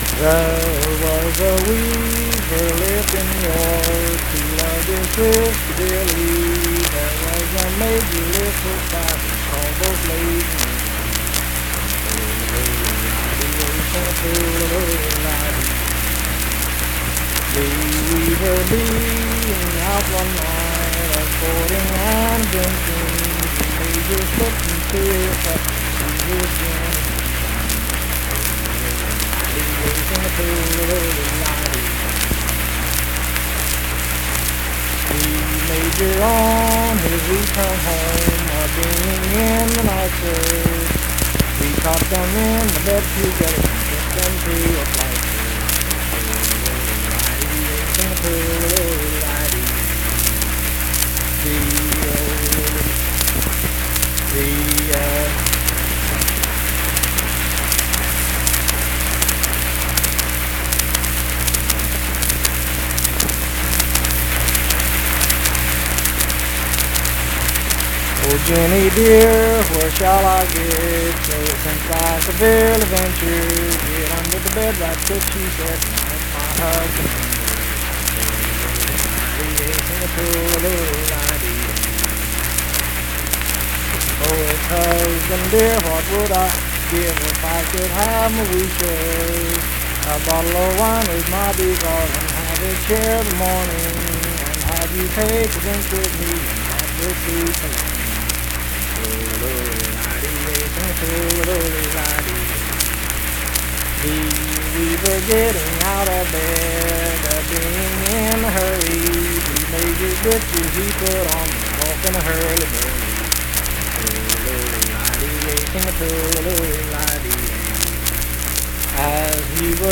Unaccompanied vocal music
Verse-refrain 9(4).
Voice (sung)
Pleasants County (W. Va.), Saint Marys (W. Va.)